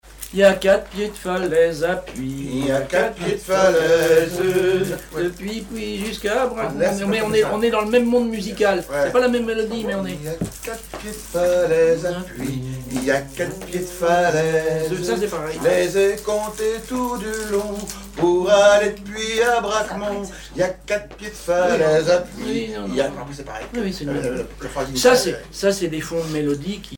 Genre énumérative
Chansons et commentaires
Pièce musicale inédite